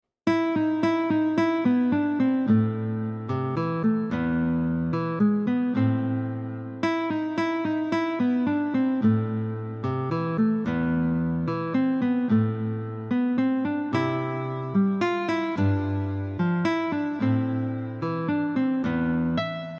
easy guitar tabs